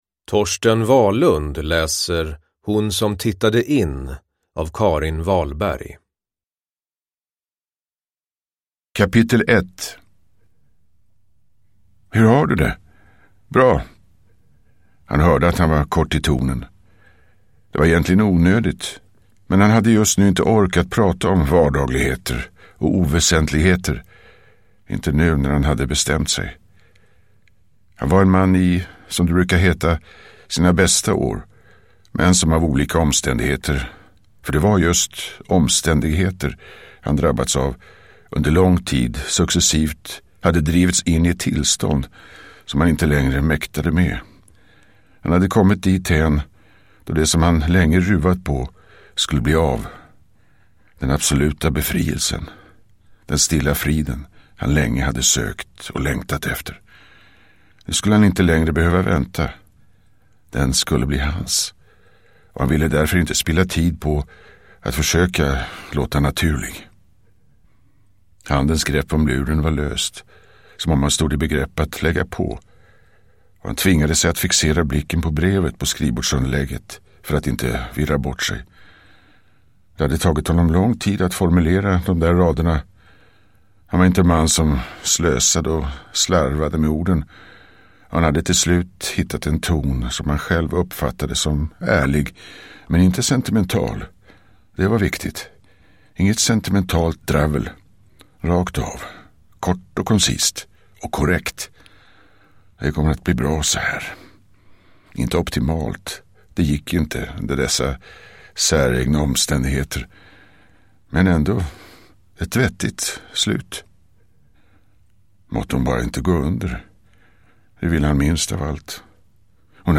Uppläsare: Torsten Wahlund
Ljudbok